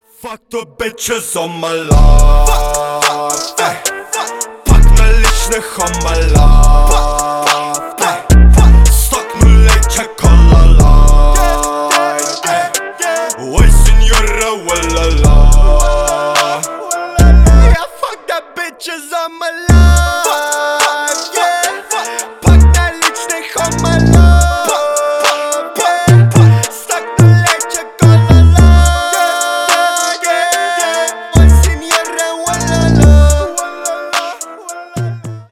• Качество: 320, Stereo
мужской голос
русский рэп
мощные басы
качающие